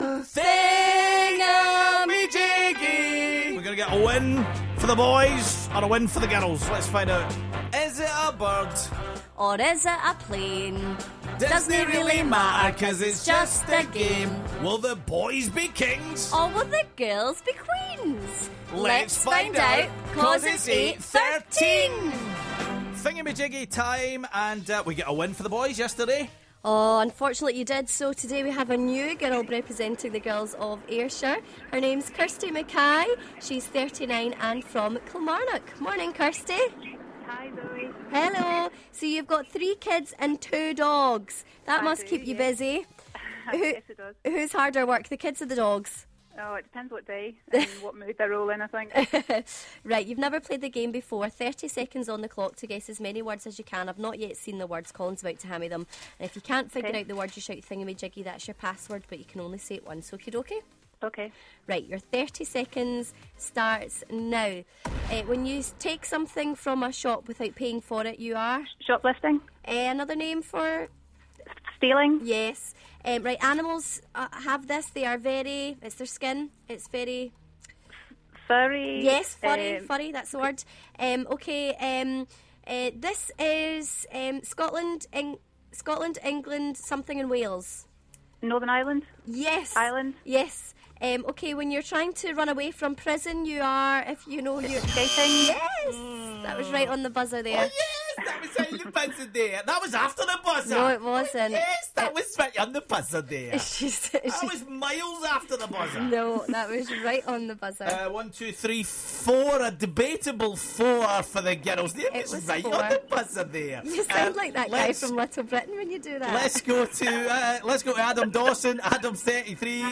It was a sing along game this morning, how well do you know your musicals?